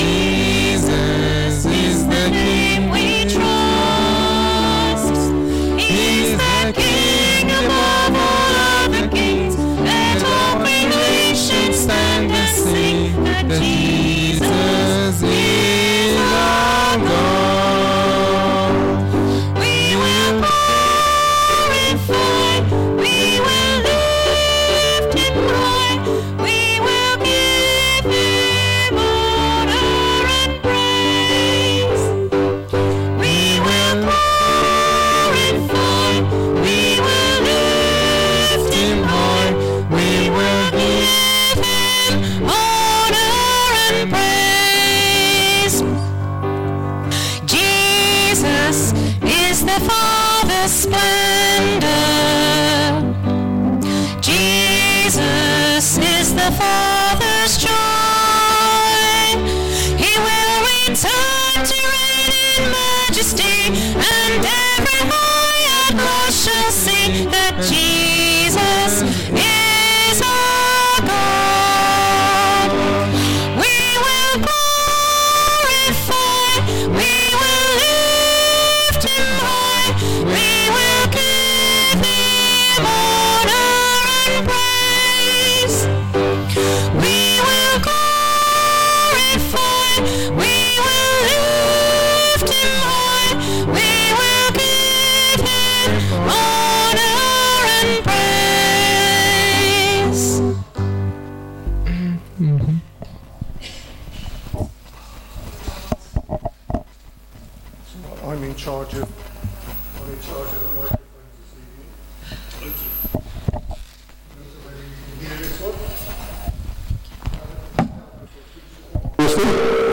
Communion service - Sittingbourne Baptist Church
Join us for our monthly evening communion service.